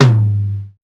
Tr8 Tom 02.wav